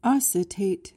PRONUNCIATION: (OS-i-tayt) MEANING: verb intr.: To yawn or gape.